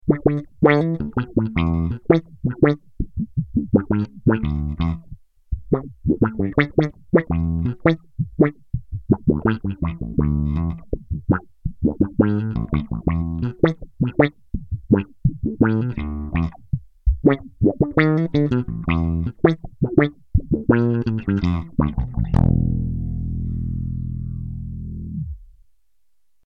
envelope filter